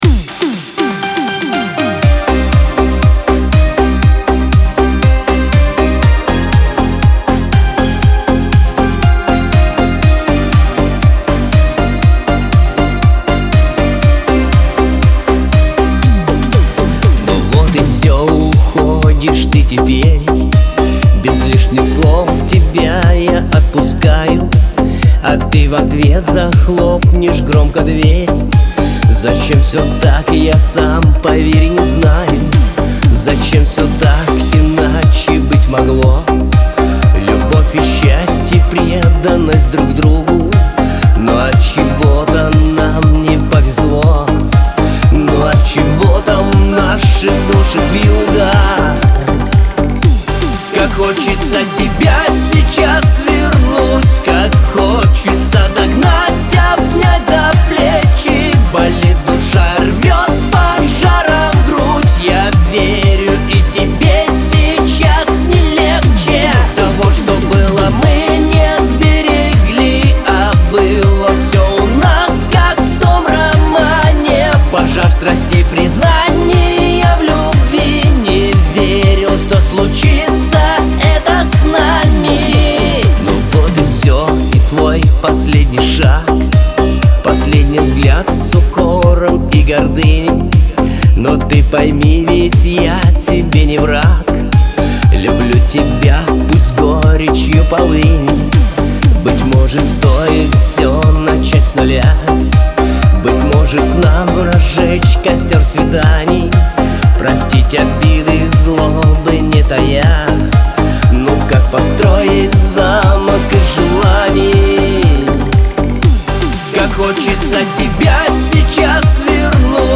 эстрада , pop